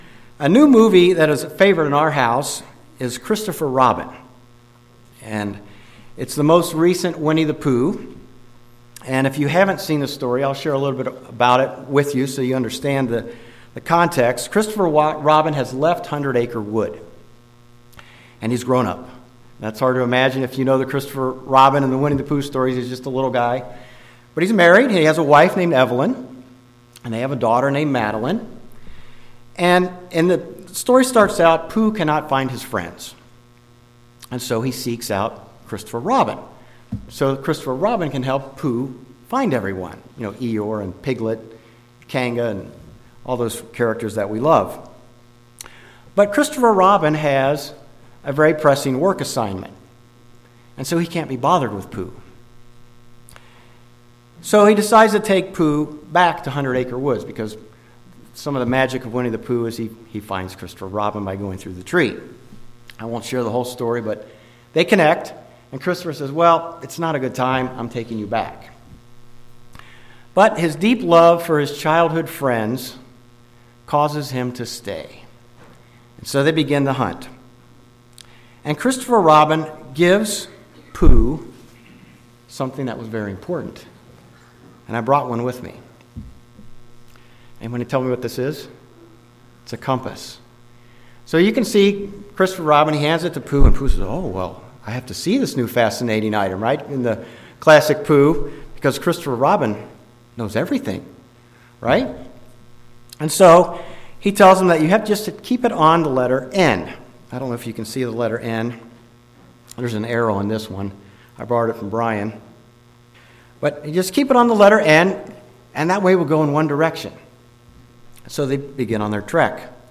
This sermon covers three points to help us accomplish this in our life.
Given in Ft. Wayne, IN